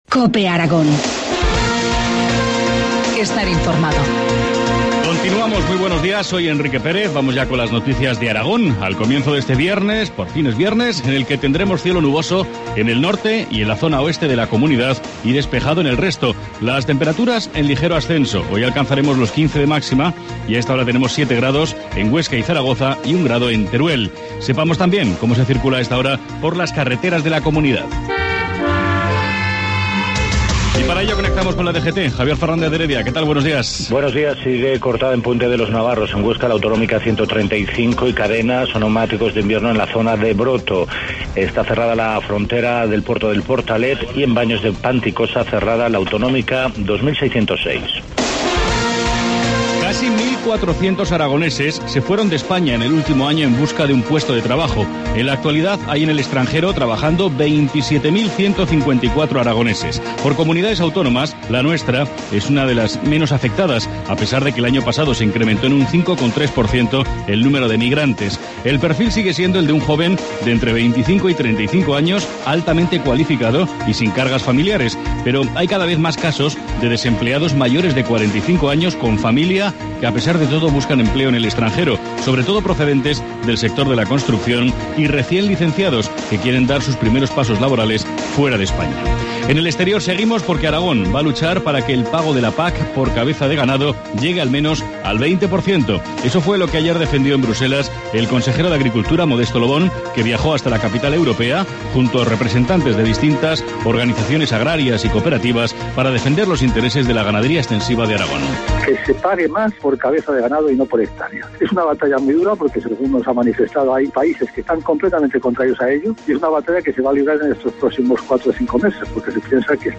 Informativo matinal, viernes 15 de febrero, 7.25 horas